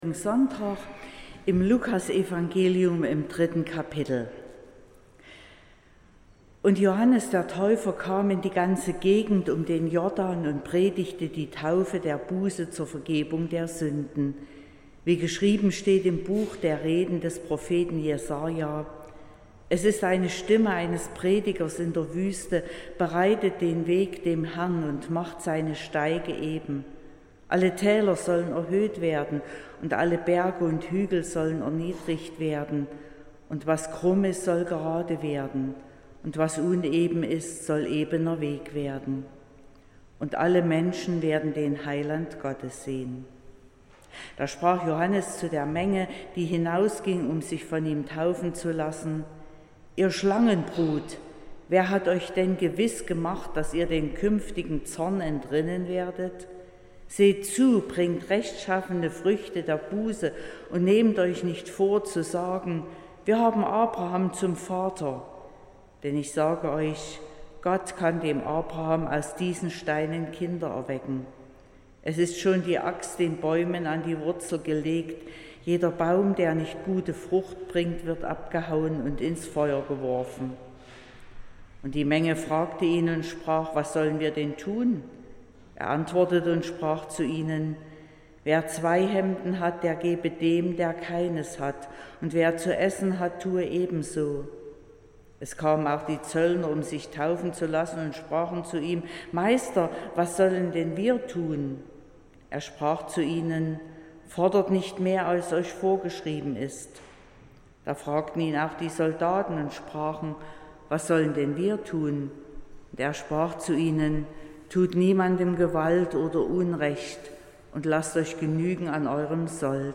14.12.2025 – Gottesdienst
Predigt und Aufzeichnungen